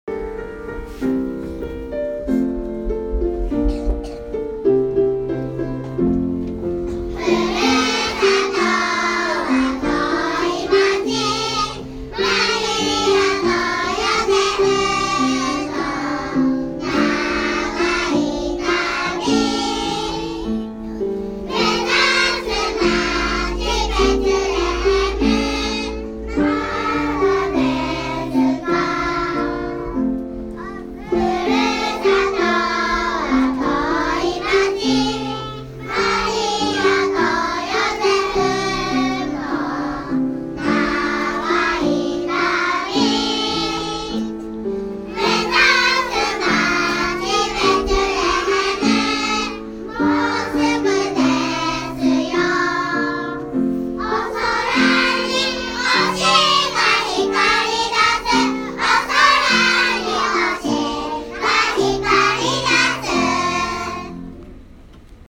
吉野の場合は、Aさんが聖劇、B、Cさんたちはコーラス隊として観客席から参加する。
確かに、今日のB、Cさんのコーラス隊はかねてよりギアが上がっていたように思う。小さな口を思い切り開けての熱傷が続いた。